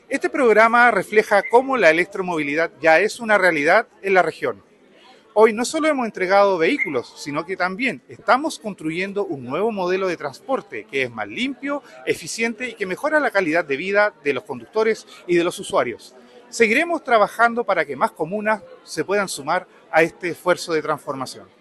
El seremi de Energía, Danilo Ulloa, afirmó que “no sólo estamos entregando vehículos, estamos construyendo un nuevo modelo de transporte que es limpio, eficiente y que mejora la calidad de vida de conductores y pasajeros”.